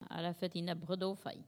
Collectif-Patois (atlas linguistique n°52)
Locution